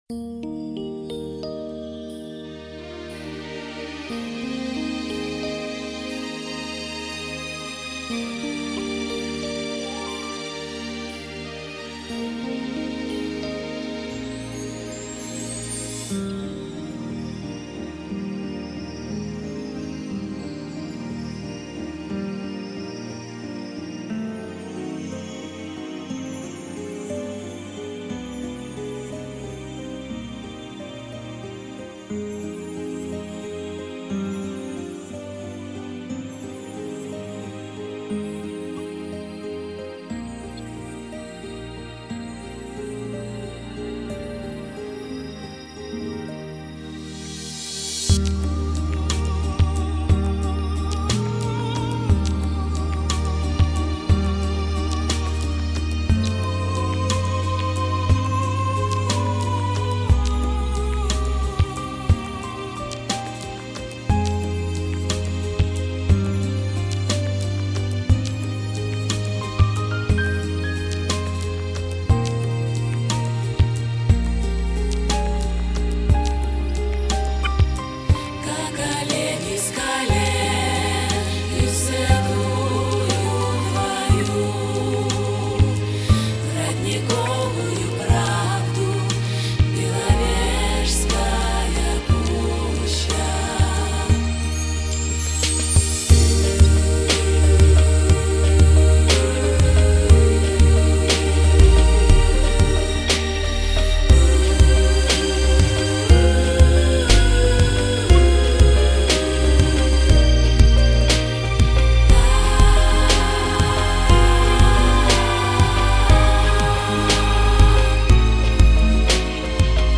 (минус, бэк)